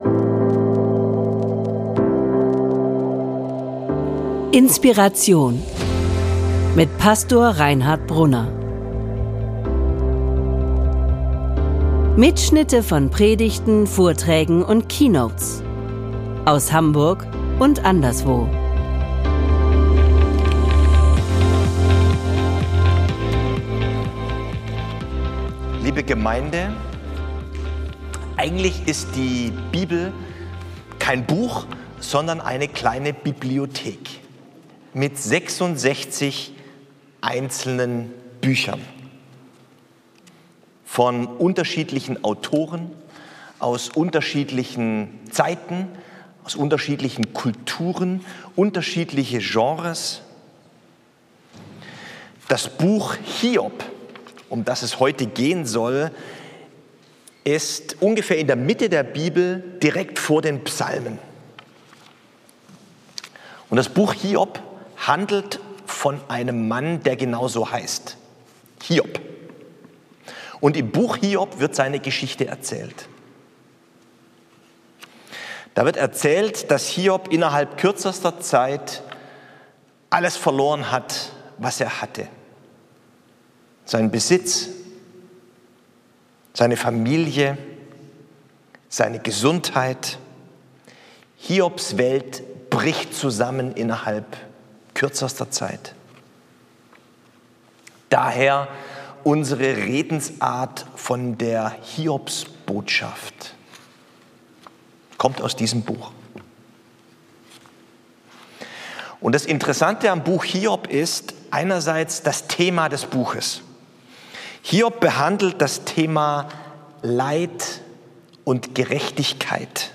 Hiobs Botschaft...von der Hoffnung ~ INSPIRATION - Predigten und Keynotes Podcast